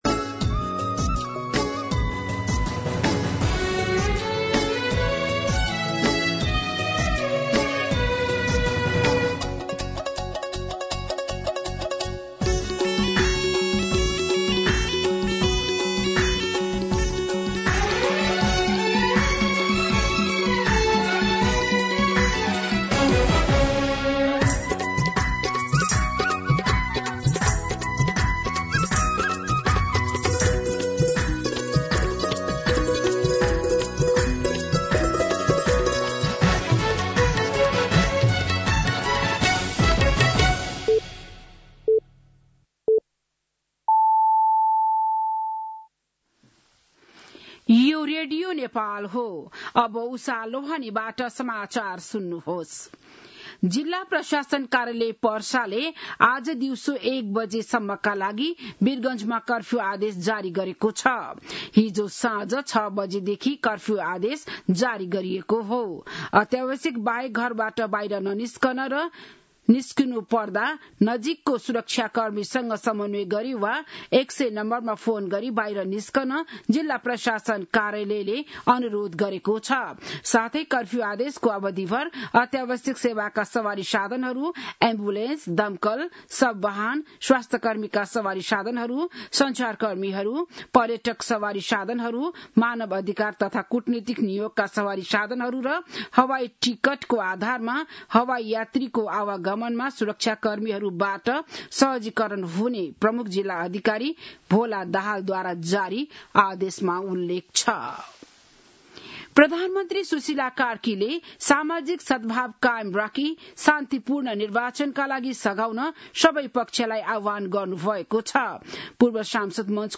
बिहान ११ बजेको नेपाली समाचार : २२ पुष , २०८२